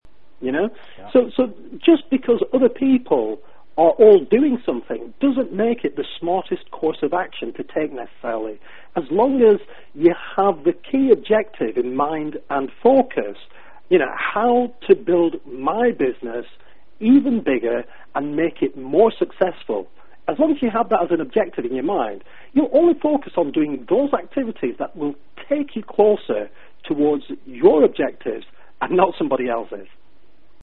Complete MP3 Audiobook in 3 parts Total 2 hour 31 minutes